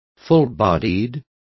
Also find out how generosas is pronounced correctly.